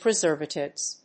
/prʌˈzɝvʌtɪvz(米国英語), prʌˈzɜ:vʌtɪvz(英国英語)/